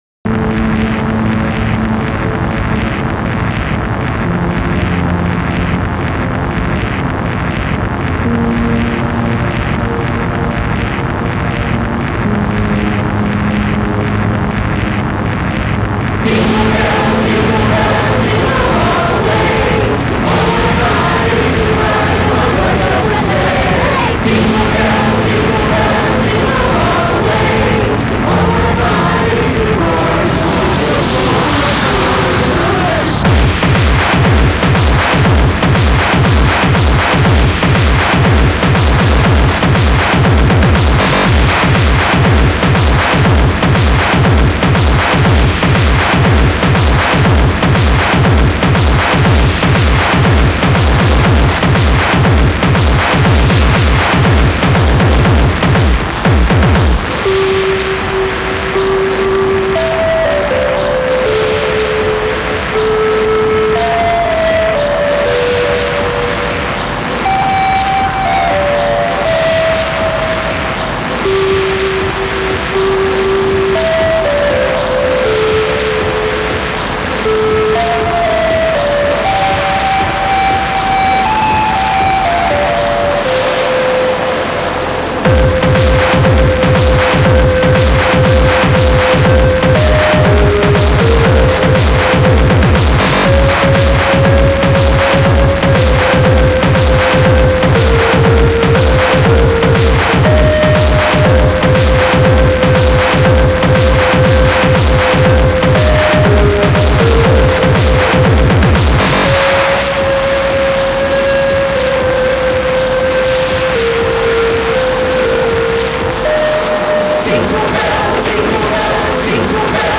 Industrial, Rhythmic Noise, xmas, Christmas